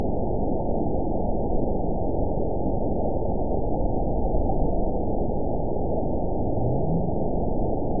event 920348 date 03/17/24 time 23:37:49 GMT (1 year, 1 month ago) score 9.65 location TSS-AB01 detected by nrw target species NRW annotations +NRW Spectrogram: Frequency (kHz) vs. Time (s) audio not available .wav